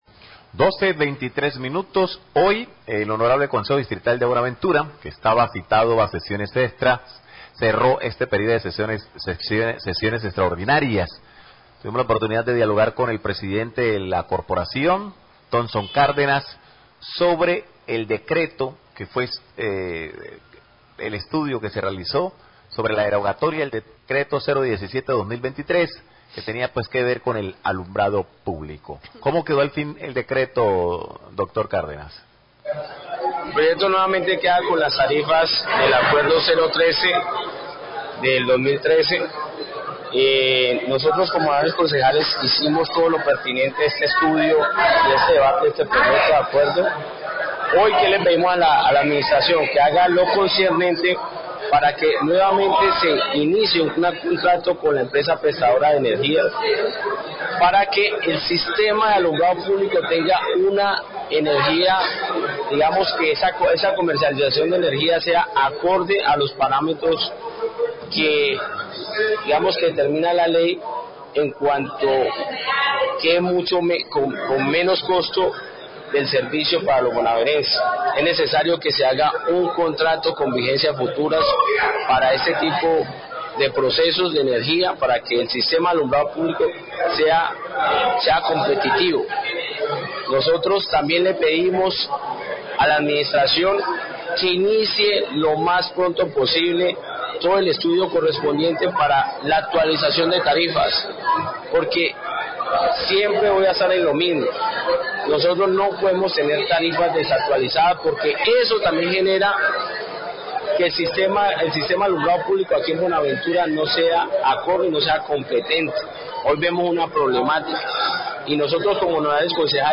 Radio
El presidente del Concejo Distrital de Buenaventura habla de la derogatoria  del decretó que modificó las tarifas de alumbrado público. Las tarifas quedarán como estaban antes del 2013.